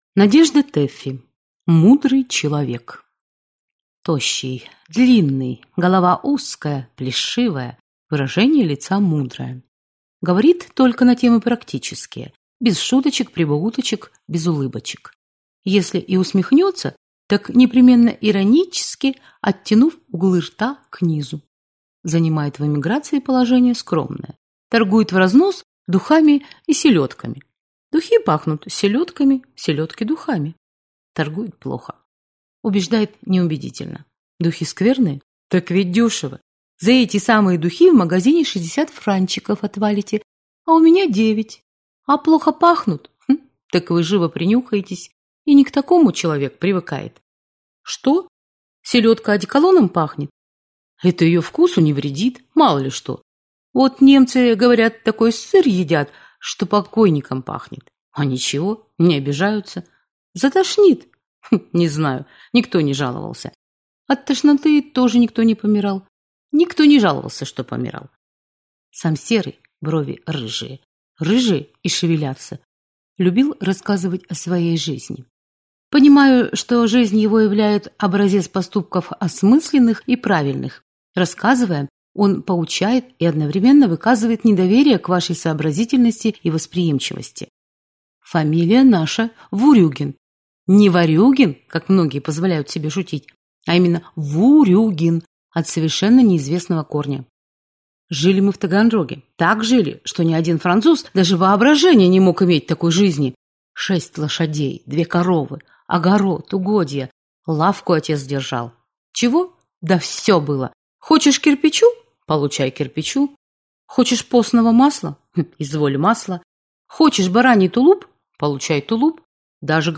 Аудиокнига Мудрый человек | Библиотека аудиокниг